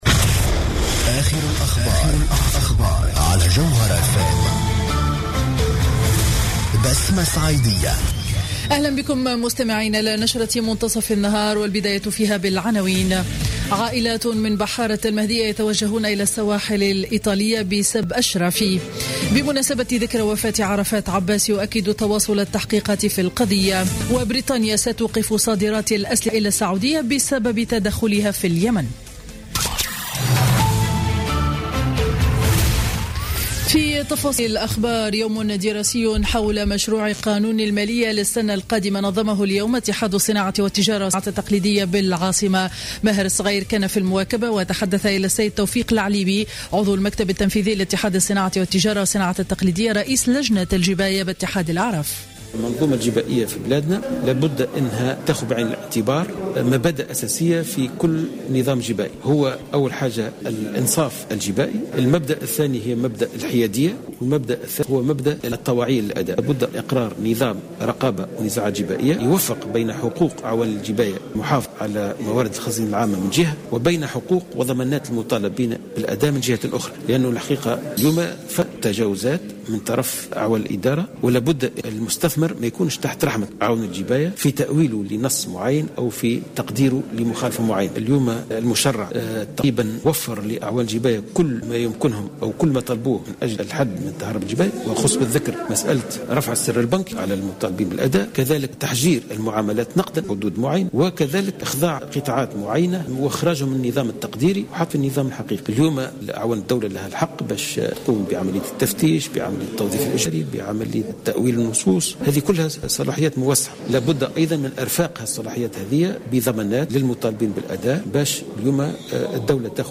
نشرة أخبار منتصف النهار ليوم الاربعاء 11 نوفمبر 2015